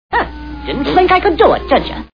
Looney Toons TV Show Sound Bites